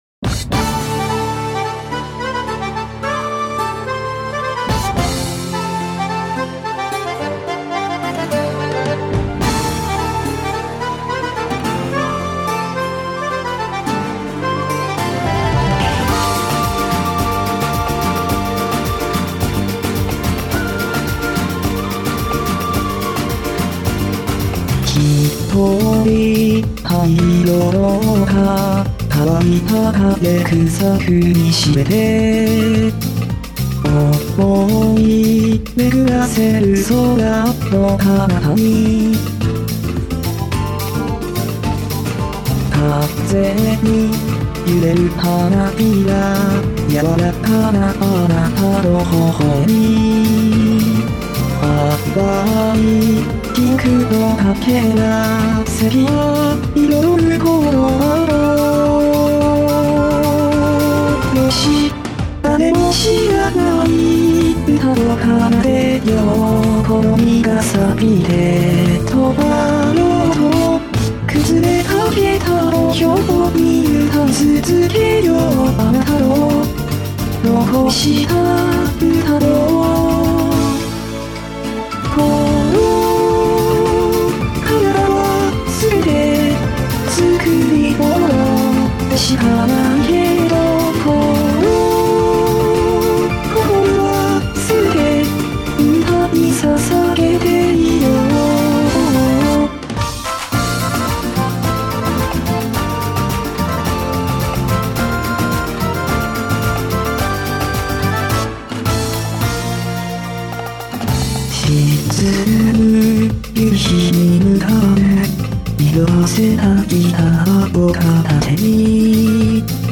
１番が暗音源で２番は静音源でハモリが若干ささやき音源になってます。
平→暗（G3?)・↓→若干ささやき(G3?)・↓↓→静(D#3?)